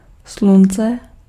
Ääntäminen
France : le soleil: IPA: [lə sɔ.lɛj]